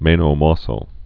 (mānō môssō, mĕnō)